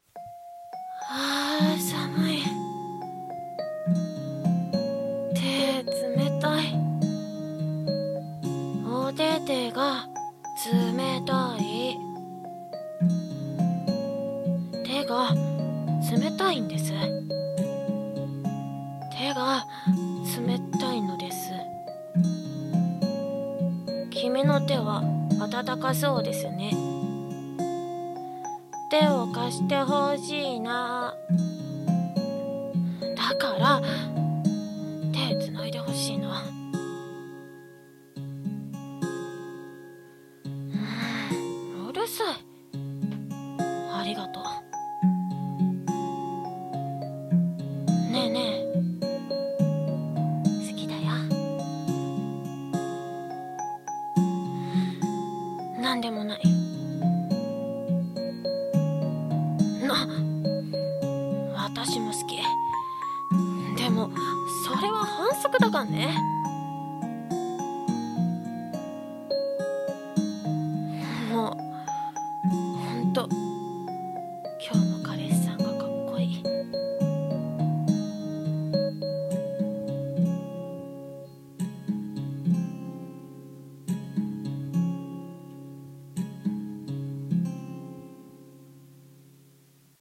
【二人声劇】